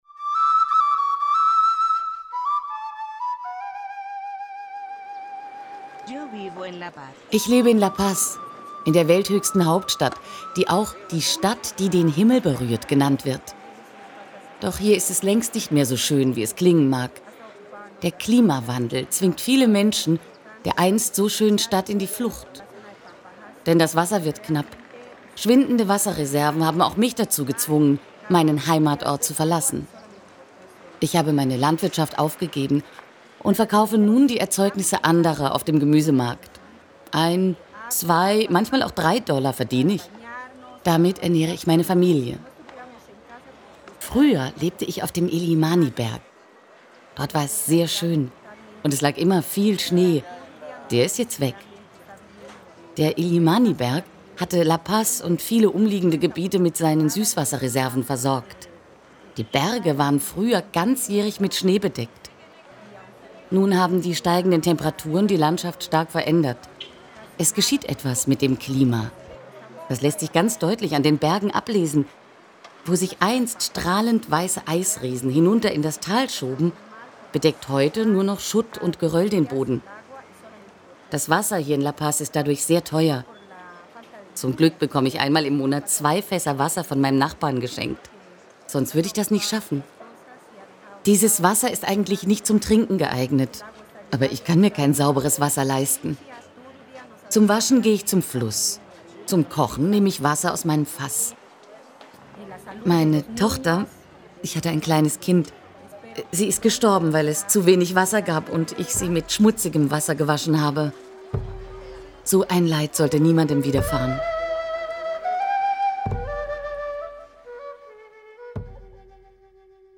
Eine Marktfrau erzählt: